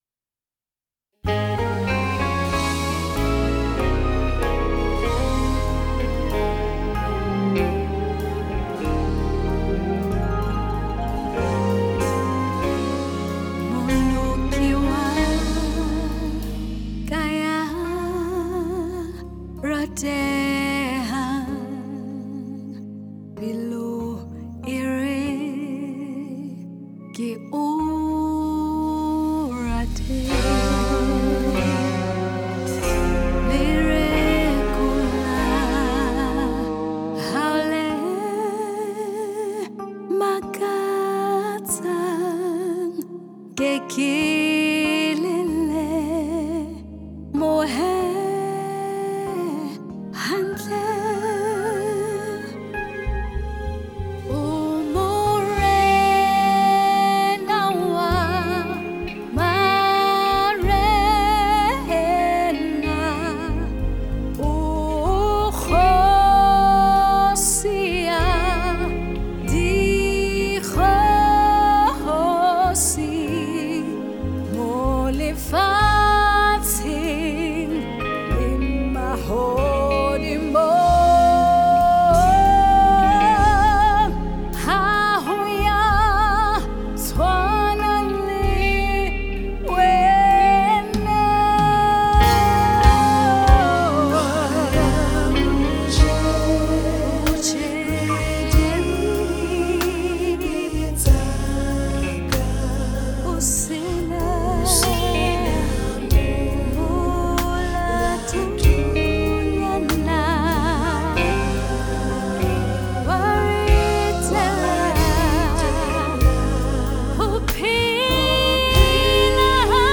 Gospel artiste